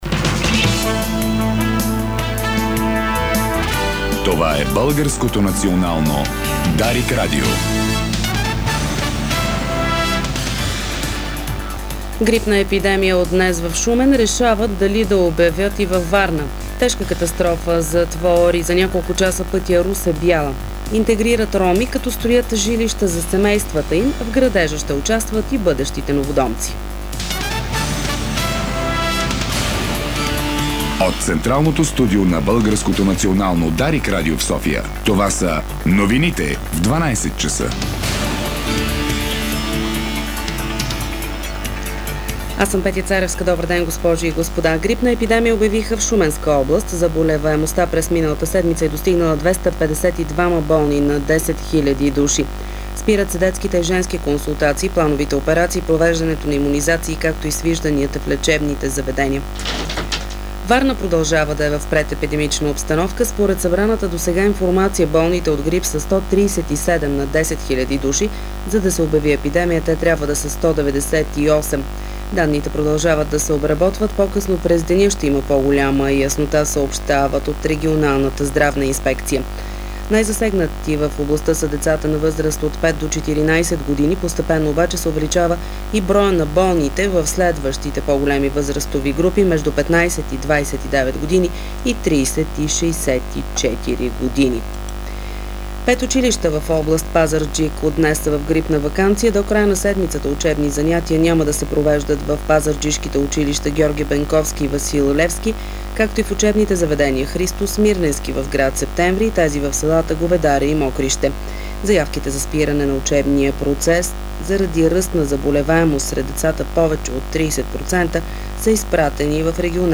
Обедна информяционна емисия - 24.01.2011 г.